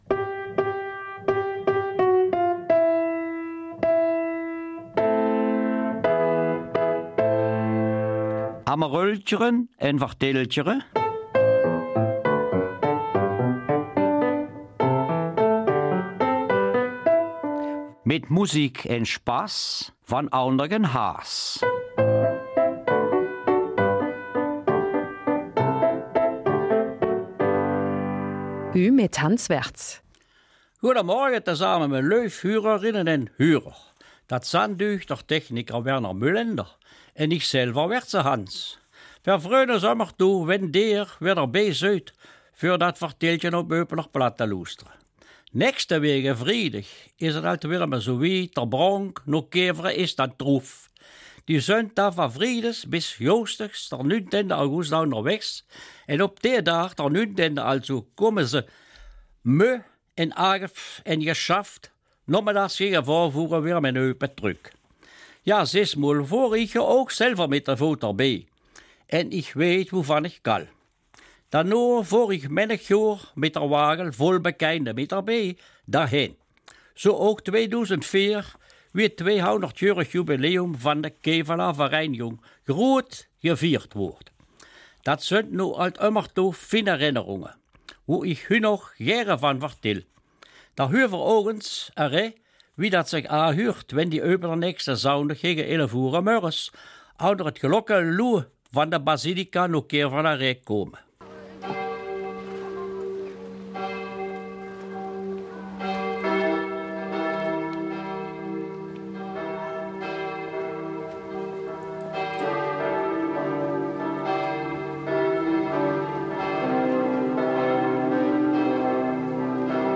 Eupener Mundart: Die Kriegszeit mit Kinderaugen betrachtet